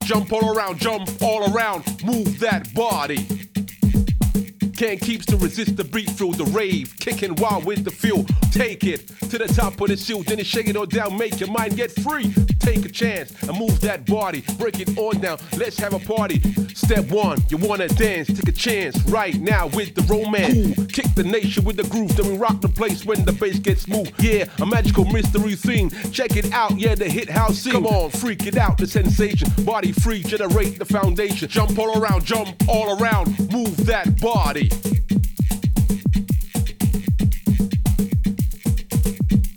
Rap (bucle)
melodía
rap
repetitivo
ritmo
sintetizador
Sonidos: Voz humana